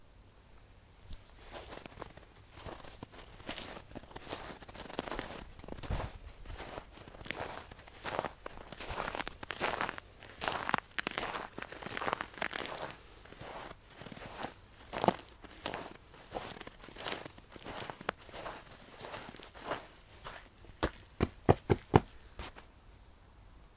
Footsteps in snow. I hope.
85575-footsteps-in-snow-i-hope.mp3